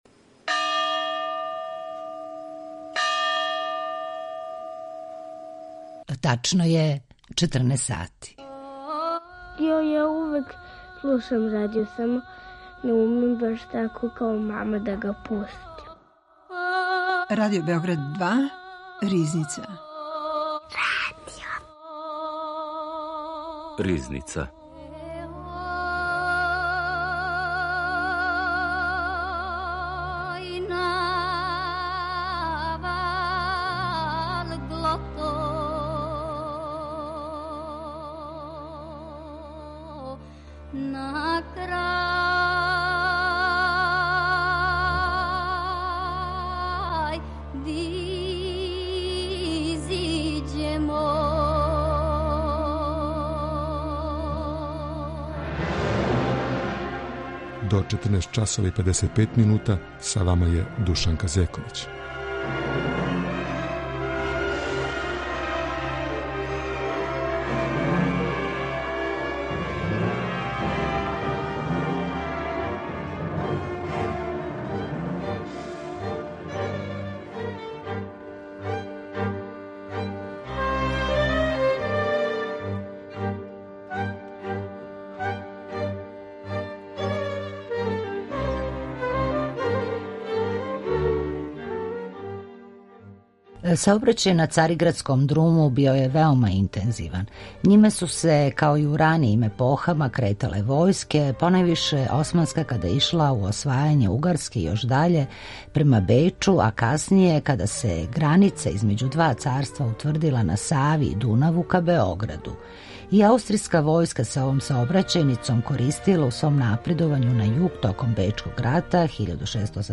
Гошћа историчарка уметности